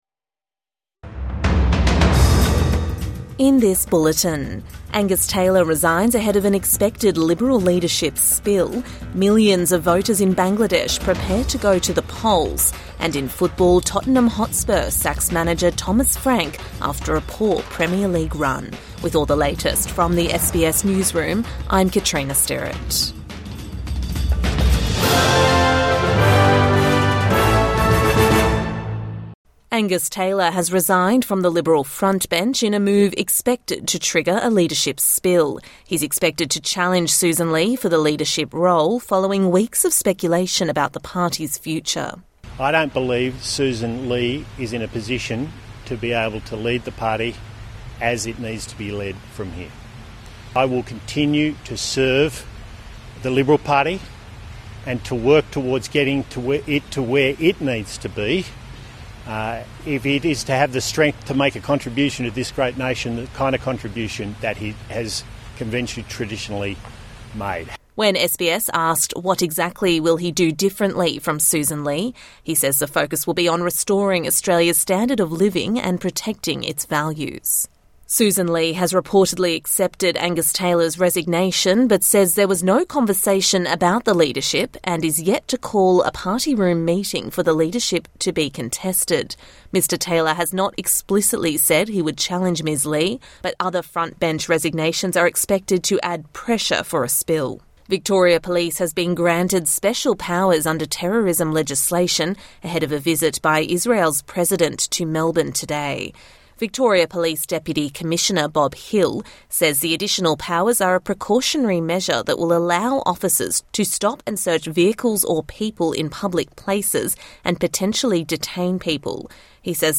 Angus Taylor resigns ahead of an expected Liberal leadership spill | Morning News Bulletin 12 February 2026